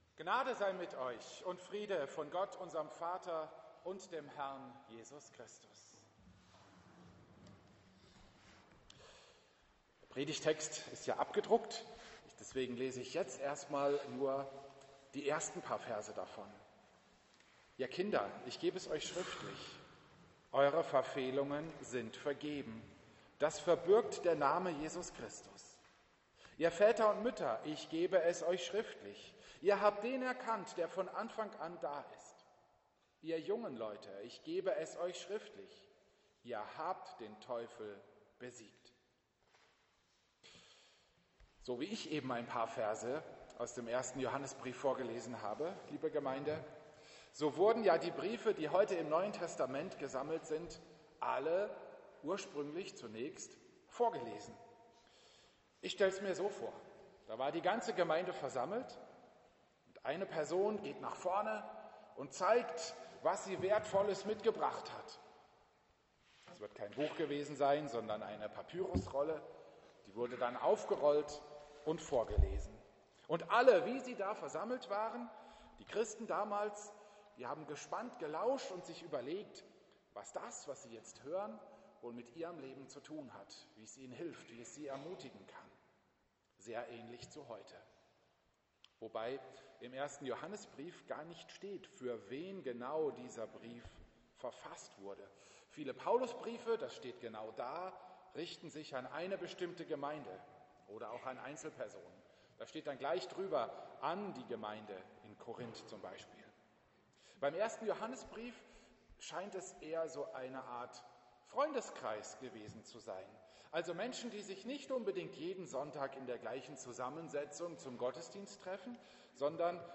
Hier geht es zur Predigt über 1. Johannes 2,12-14